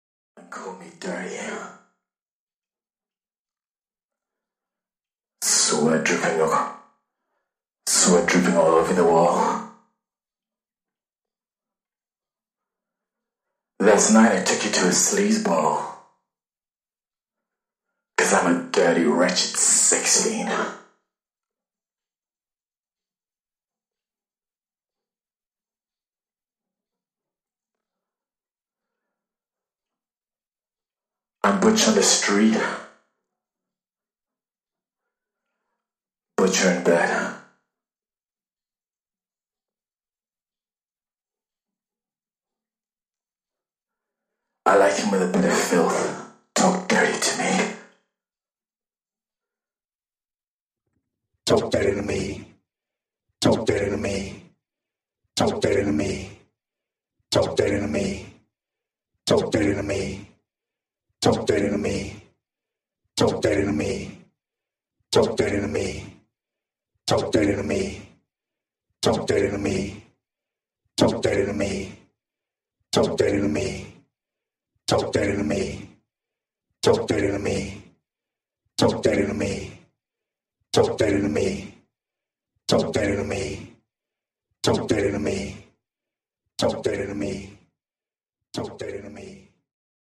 (Accapella)